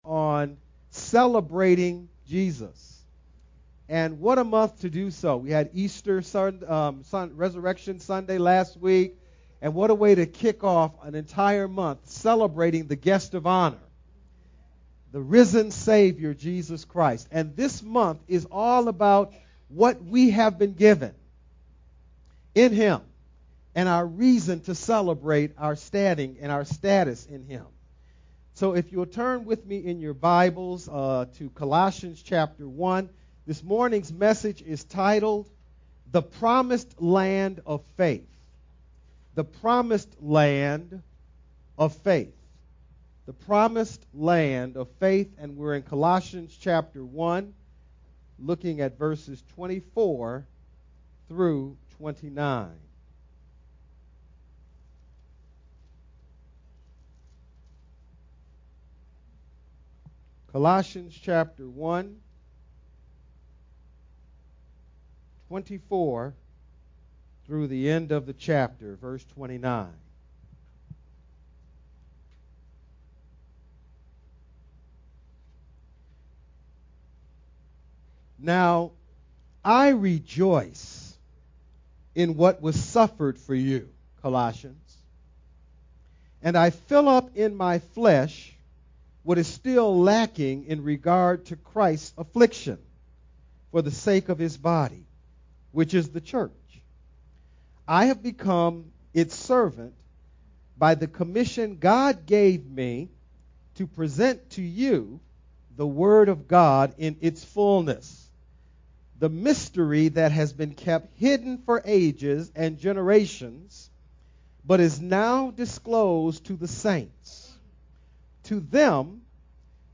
Colossians 1: 24-29 The Main Idea- The New Covenant Promised Land is experiencing the fullness of Christ. Message